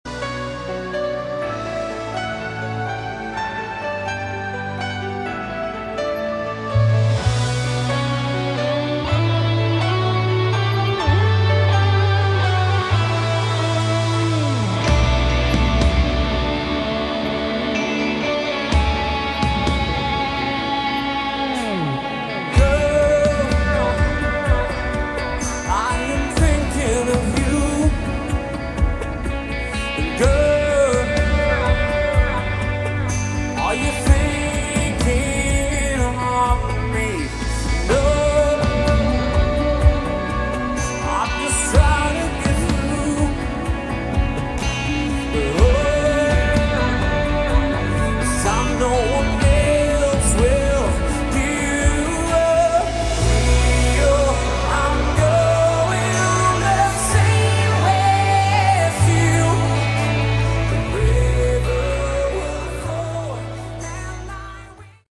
Category: Melodic Rock
lead vocals, guitars
keyboards, backing vocals
recorded at Vaasa, Elisa Stadium in Finland in summer 2022.